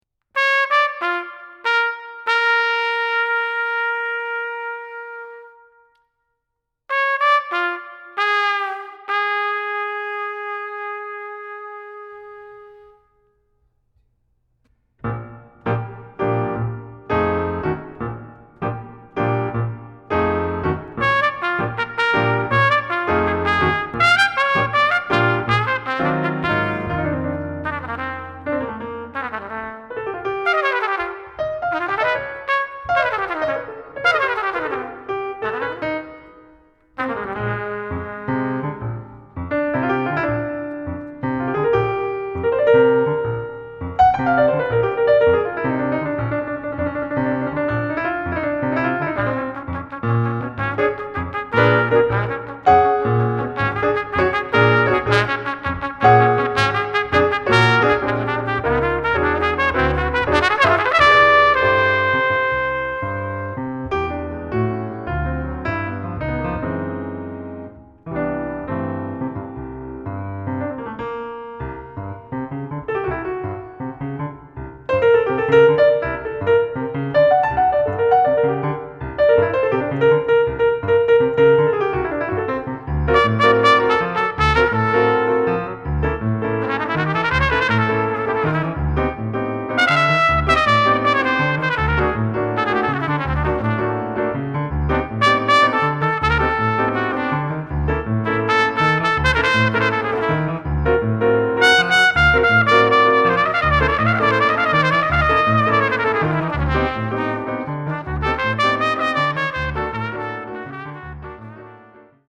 (Latin swing)
sax - flute -
trumpet - vibes - cello - violin